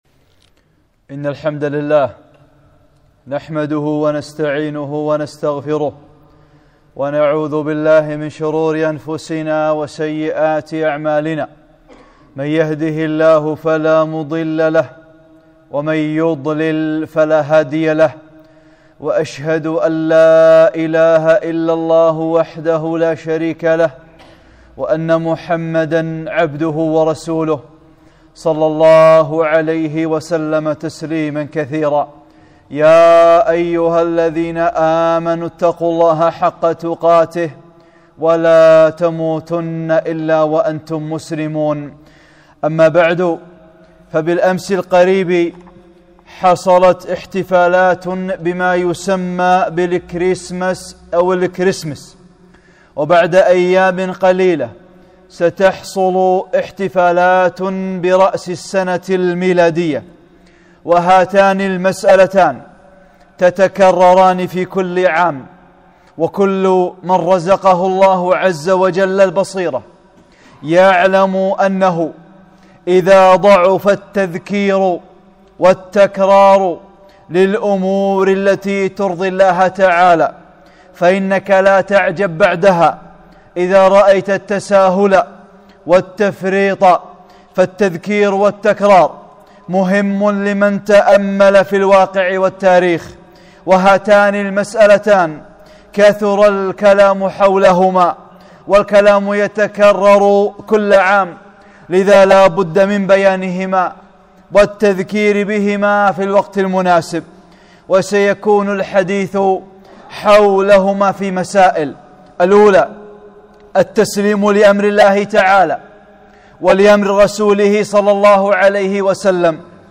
خطبة - الاحتفال بـ( الكريسمس، رأس السنة الميلادية )